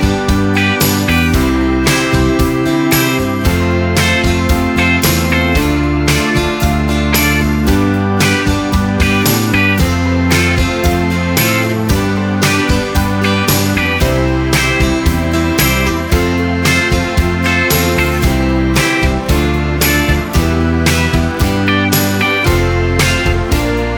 Minus Bass Pop (1980s) 3:01 Buy £1.50